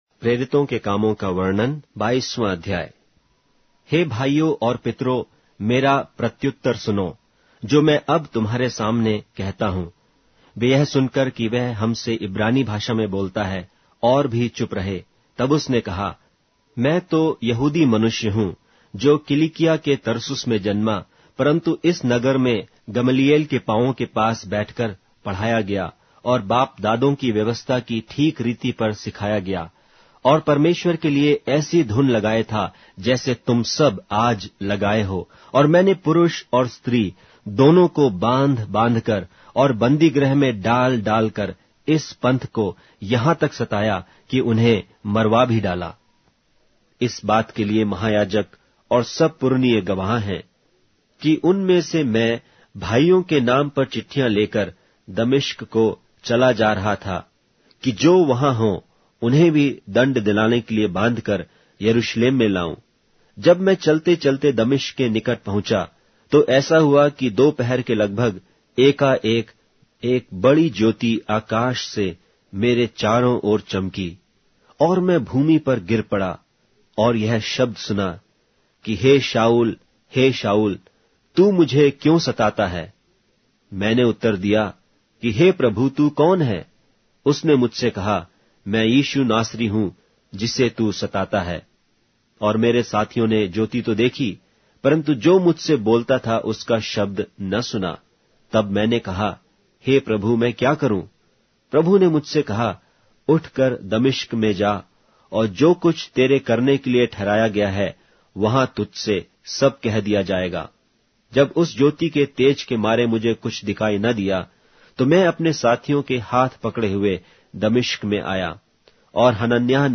Hindi Audio Bible - Acts 15 in Irvor bible version